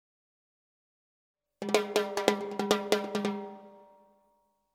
Timbales fills in 140 bpm
The timbales are with light reverb.
This package contains real timbales fills playing a variety of fills in 140 bpm.
The timbales were recorded using “ AKG C-12” mic. The timbales were recorded mono but the files are stereo for faster workflow.
And There is only light and perfect analog EQ and light compression, giving you The Opportunity to shape the sound you like in your project.